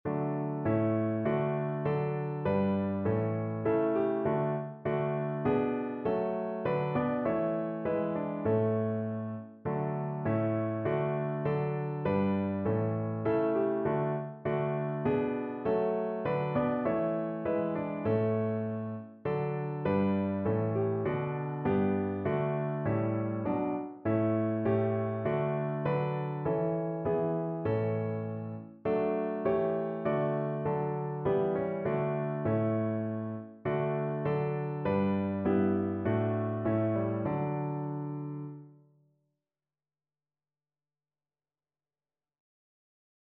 Notensatz 1 (4 Stimmen gemischt)
• gemischter Chor [MP3] 677 KB Download